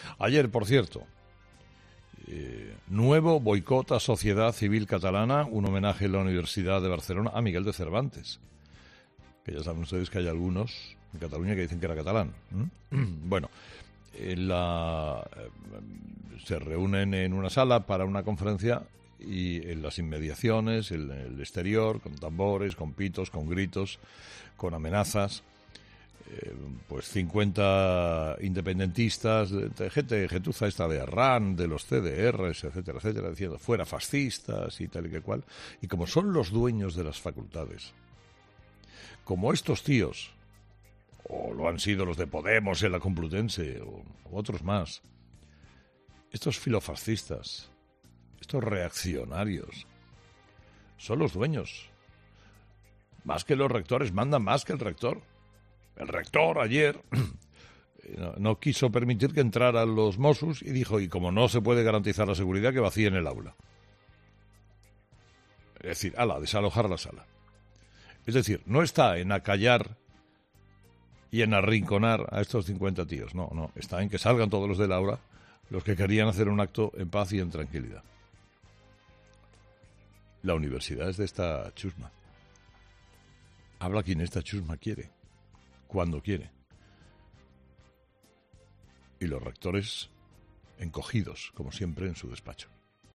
"Habla quien esta chusma quiere y cuando quiere. Y los rectores, como siempre encogidos en su despacho", ha dicho el comunicador en su monólogo de este viernes